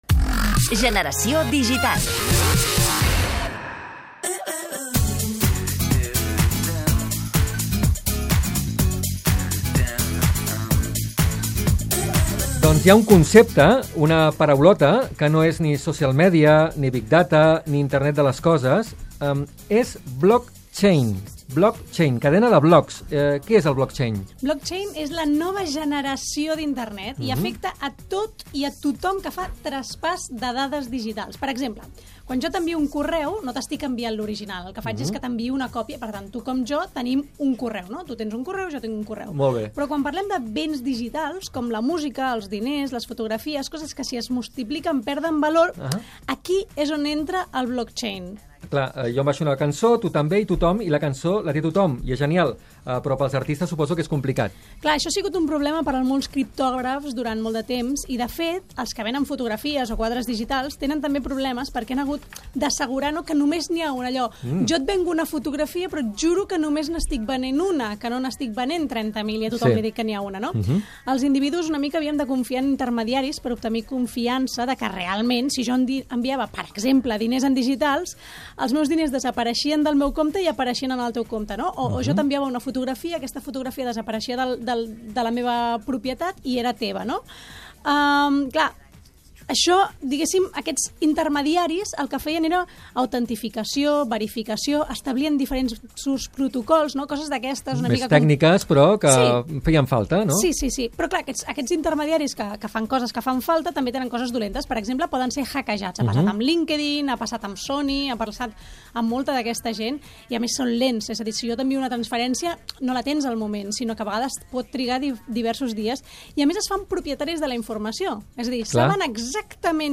Divulgació
FM